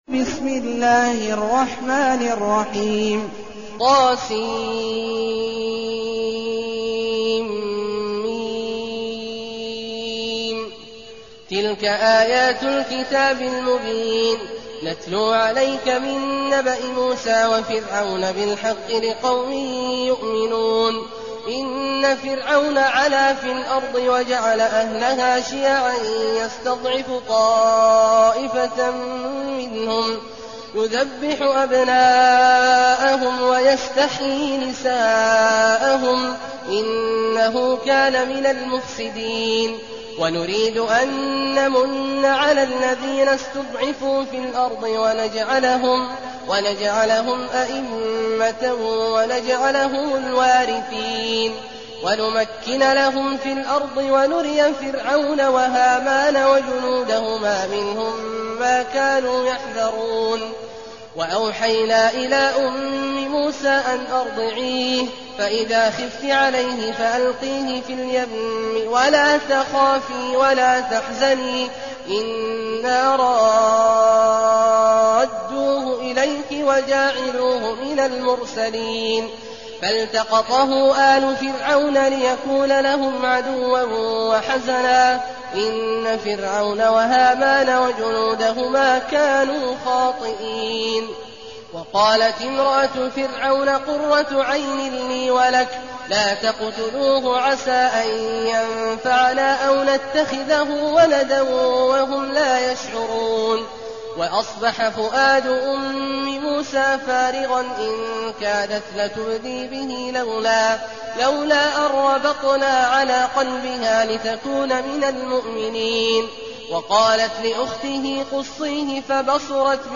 المكان: المسجد النبوي الشيخ: فضيلة الشيخ عبدالله الجهني فضيلة الشيخ عبدالله الجهني القصص